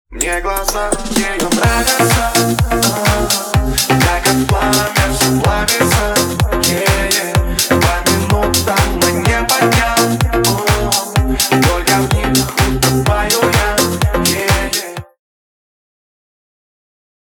• Качество: 320, Stereo
мужской вокал
громкие
remix
Club House